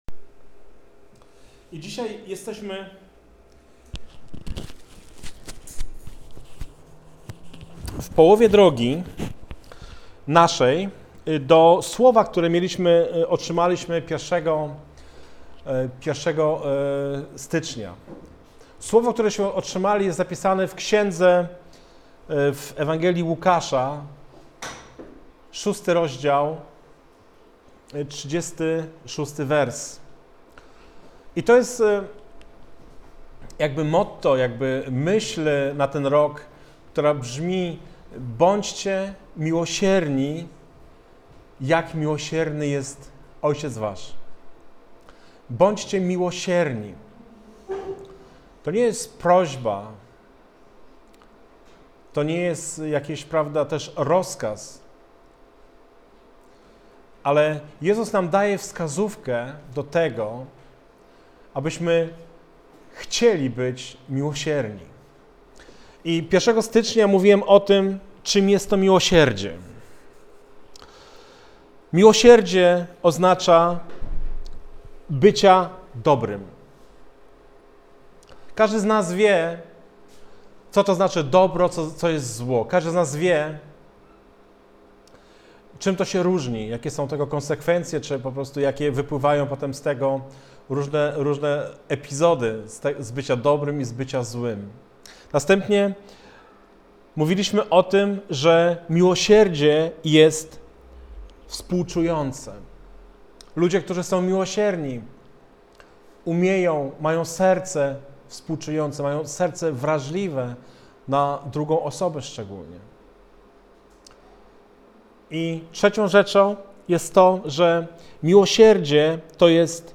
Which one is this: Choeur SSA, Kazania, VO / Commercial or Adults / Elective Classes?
Kazania